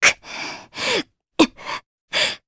Aselica-Vox_Sad_jp.wav